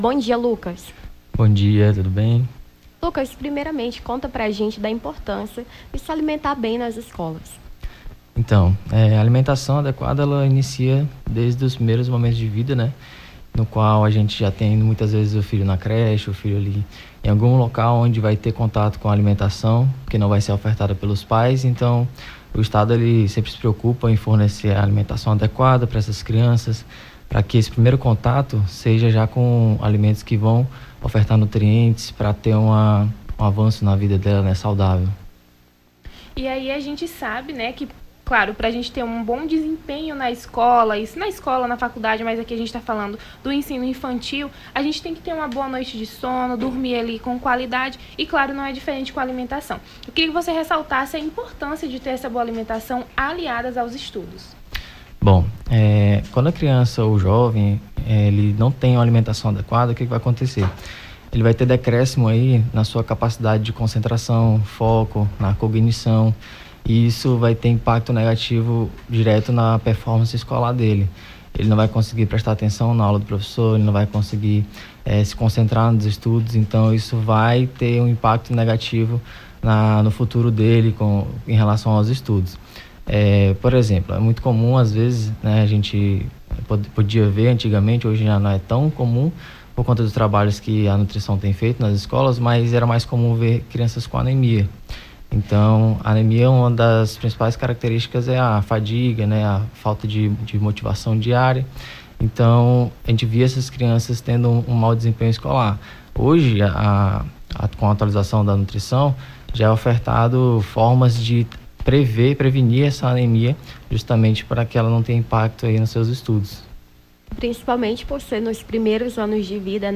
Nome do Artista - CENSURA - ENTREVISTA (ALIMENTACAO ESCOLAR) 24-10-23.mp3